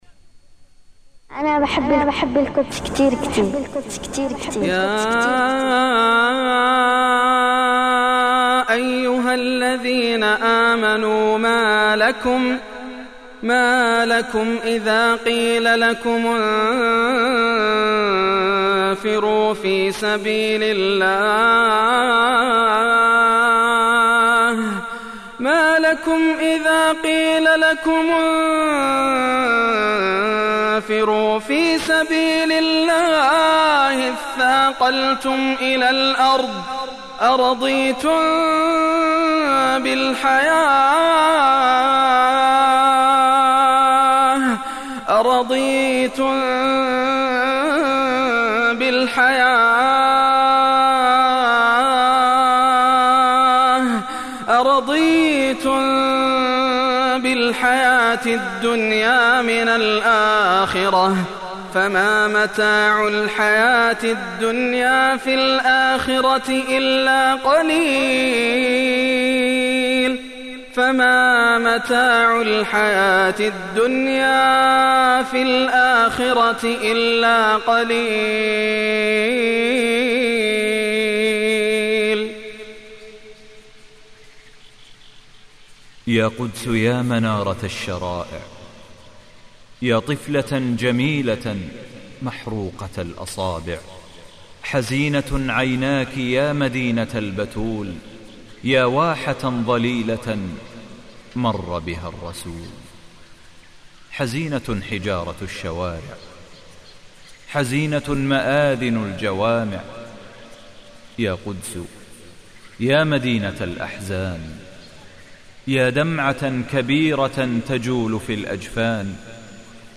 أناشيد ونغمات